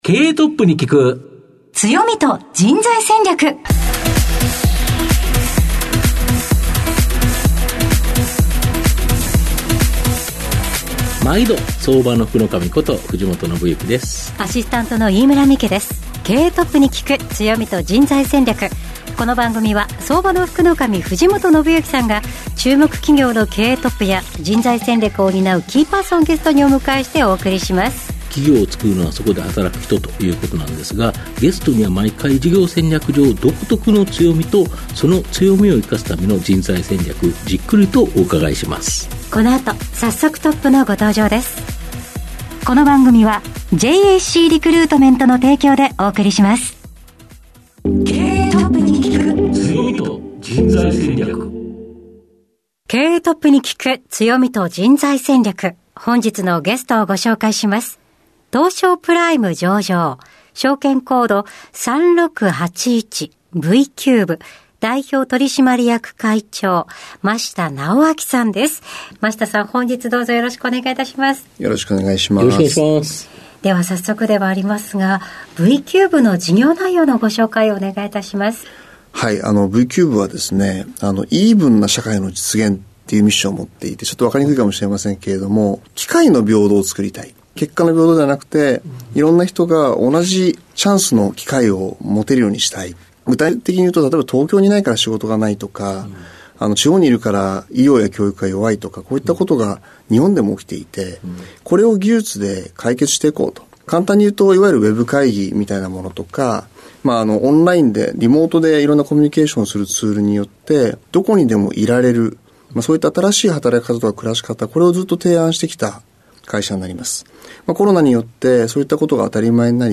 毎回注目企業1社をピックアップし経営トップをゲストにお招きし、事業の側面だけでなく人材戦略の観点からも企業の強みに迫る。トップの人柄が垣間見えるプライベートなQ&Aも。